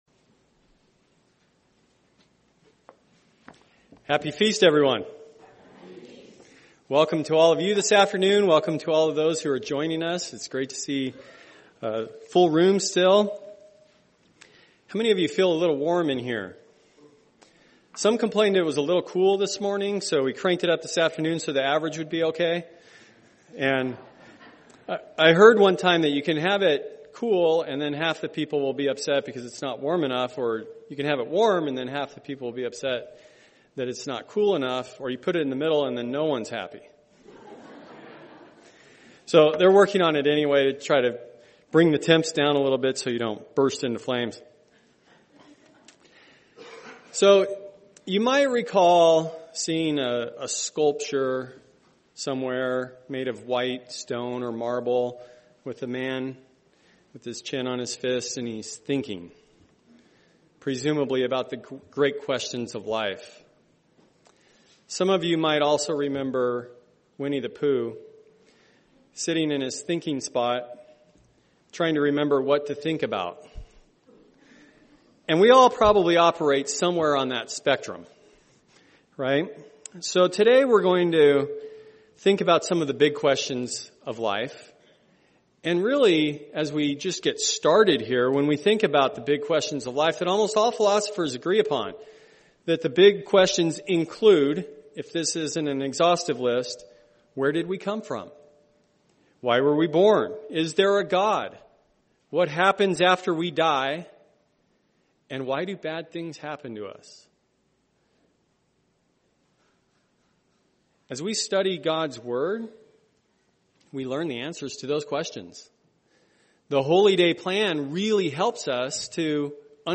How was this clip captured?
Given in Phoenix Northwest, AZ